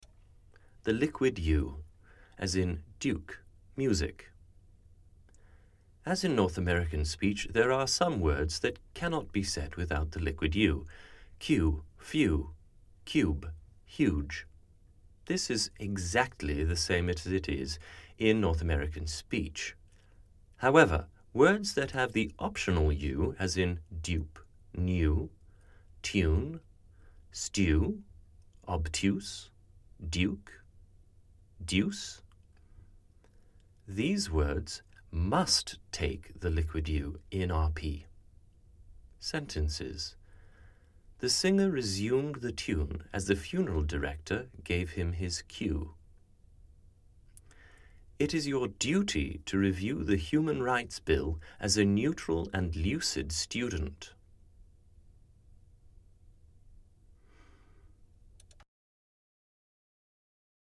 Received Pronunciation (RP)
duke music